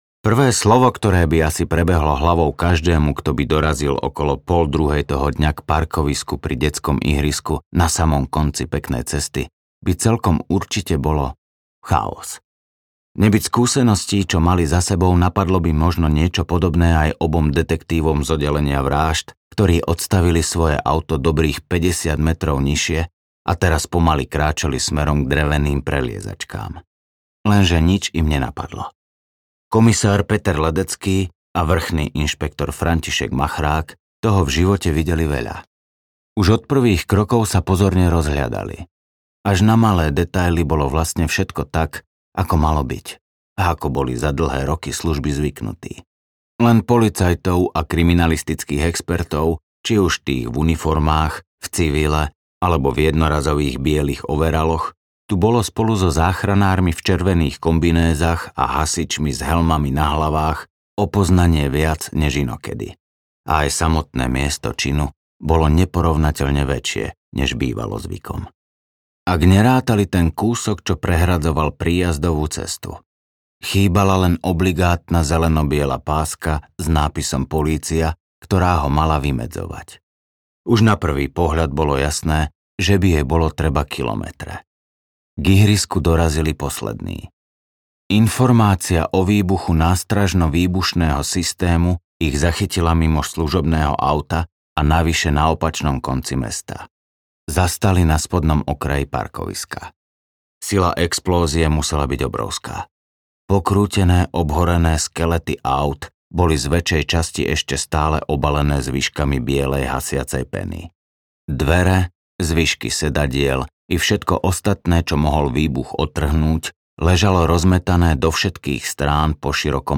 Ukázka z knihy
privela-podozrivych-audiokniha